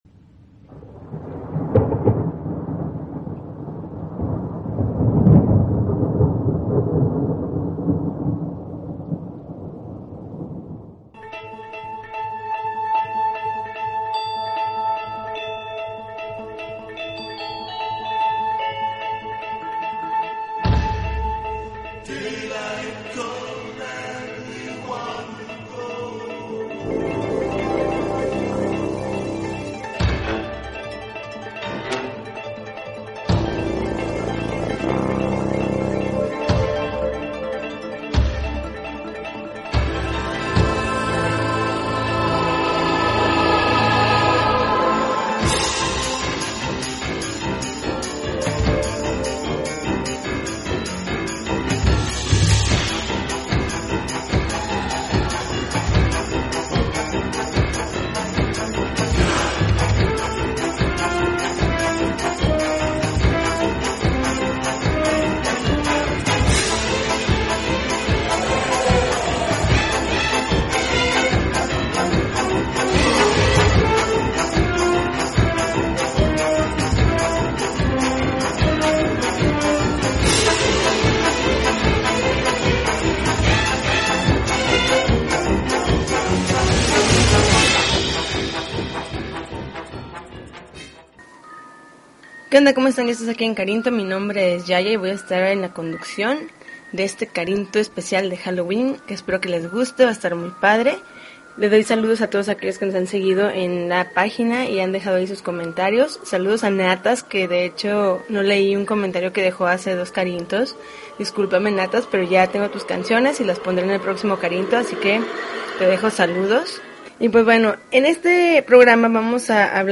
October 28, 2012Podcast, Punk Rock Alternativo